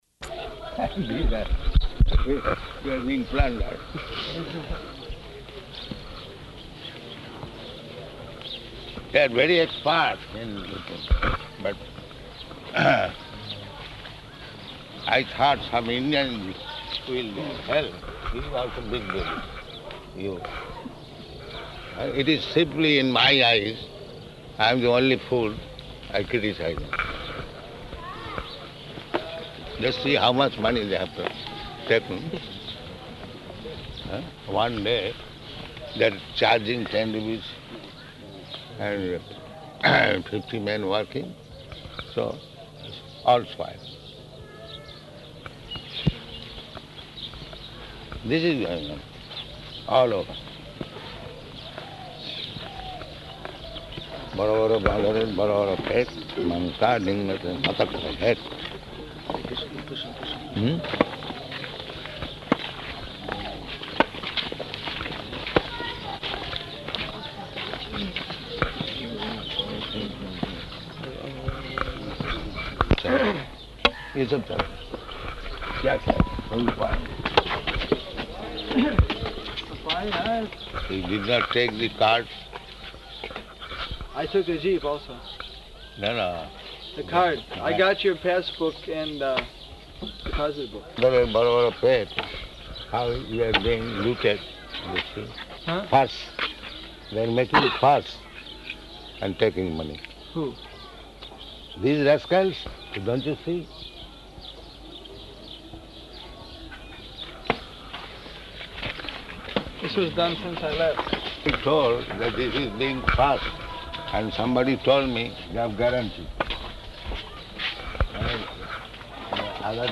Morning Walk --:-- --:-- Type: Walk Dated: March 9th 1976 Location: Māyāpur Audio file: 760309MW.MAY.mp3 Prabhupāda: [indistinct] You are being plundered.